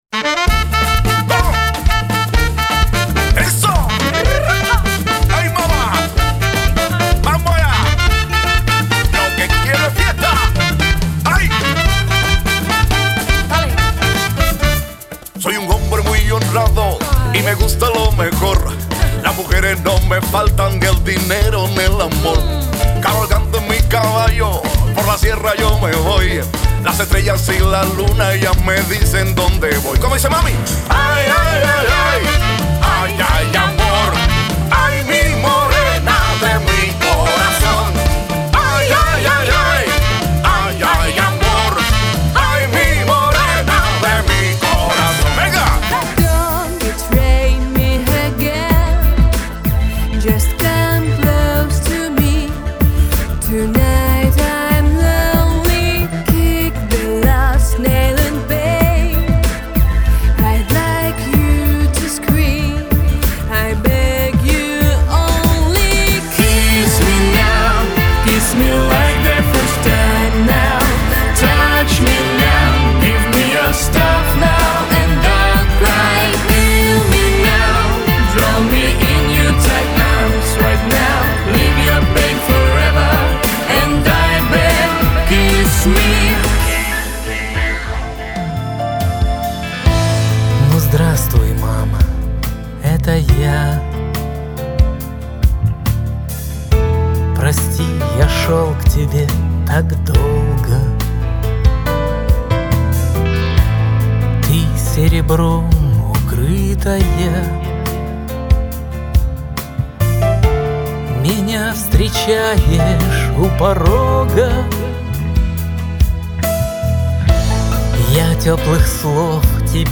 белорусско-российско-украинский электро-поп проект